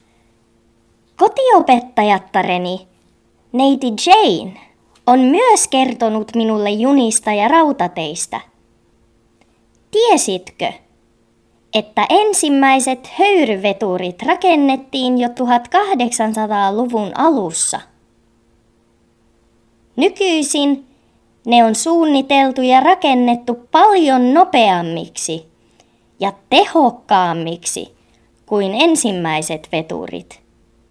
HÖYRYVETURIN ÄÄNI
Veturi2.mp3